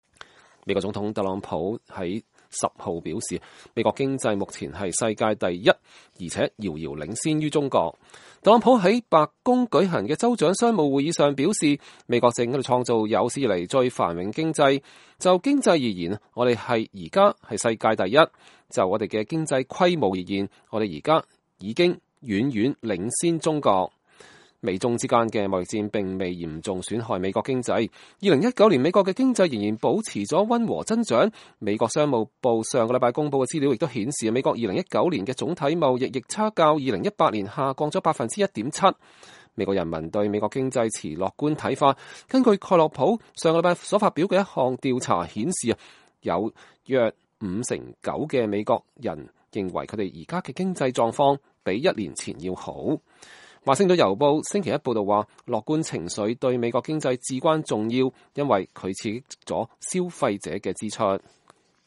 美國總統唐納德·特朗普在白宮舉行的州長的商務會議上發表講話。（2020年2月10日）